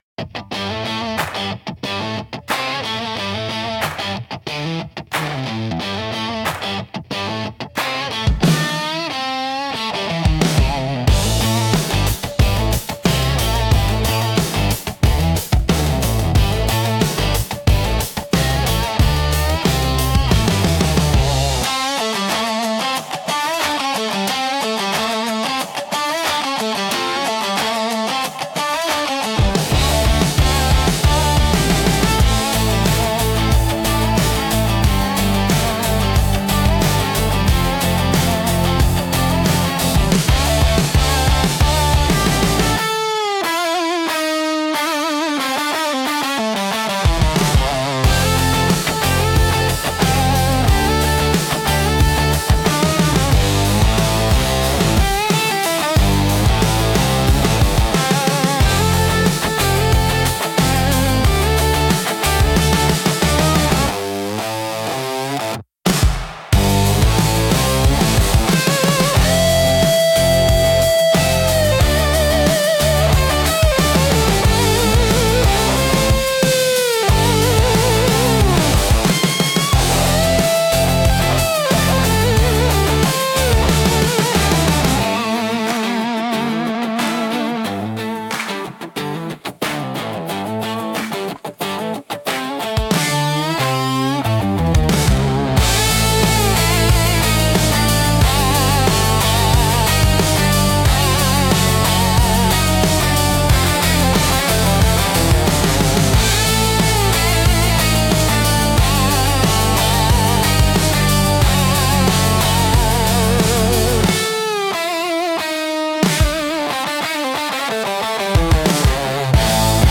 Pour illustrer concrètement, voici trois ambiances que j'ai générées avec Suno.
Studio rock
Pour une marque énergique, jeune, qui assume un ton direct et dynamique.